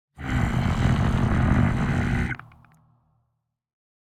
Minecraft Version Minecraft Version snapshot Latest Release | Latest Snapshot snapshot / assets / minecraft / sounds / mob / warden / angry_2.ogg Compare With Compare With Latest Release | Latest Snapshot
angry_2.ogg